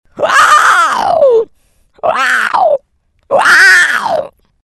Звуки вампиров
Вопль самки вампира